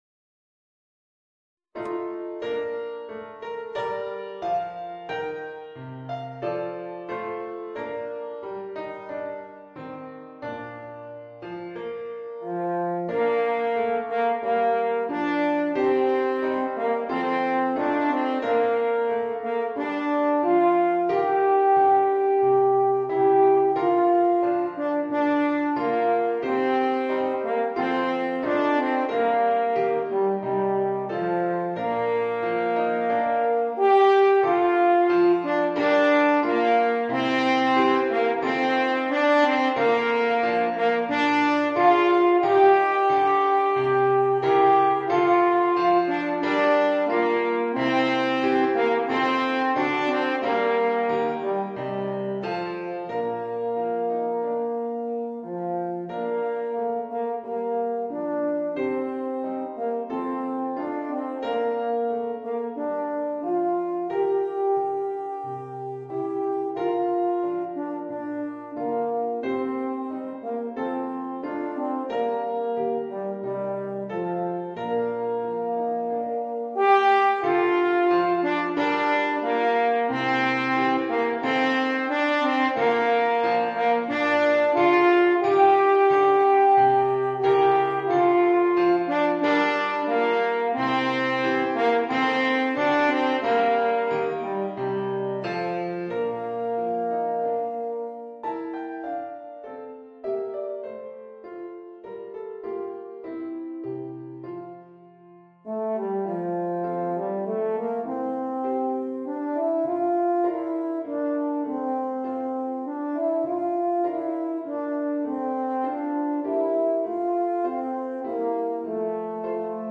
Voicing: Eb Horn w/ Audio